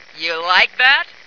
flak_m/sounds/female2/int/F2youlikethat.ogg at 46d7a67f3b5e08d8f919e45ef4a95ee923b4048b